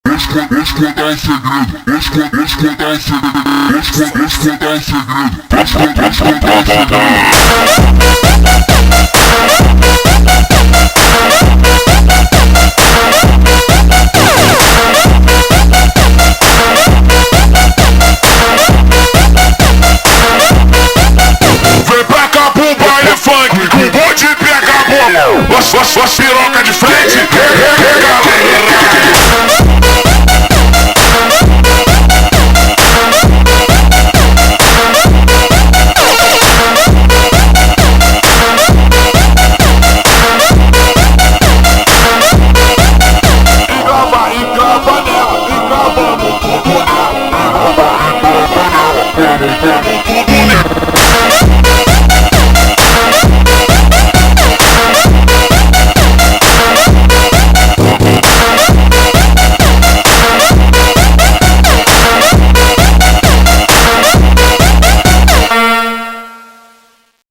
Brazilian Phonk